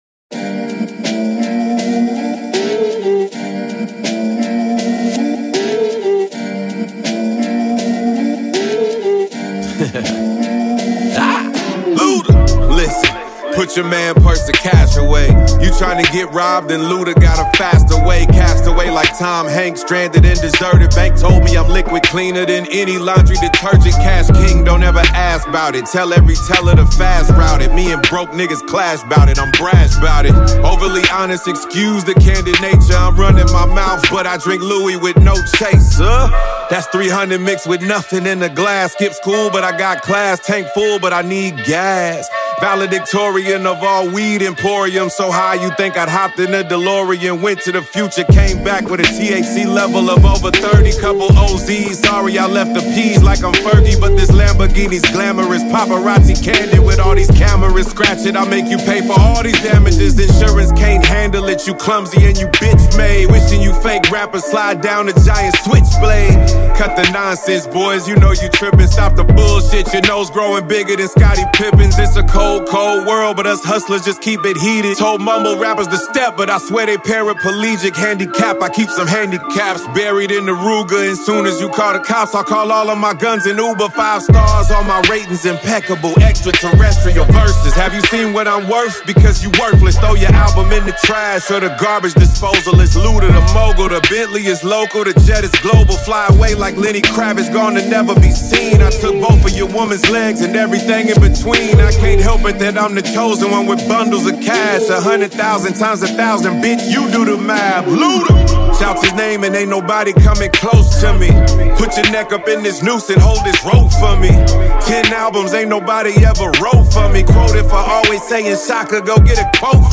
catchy melodies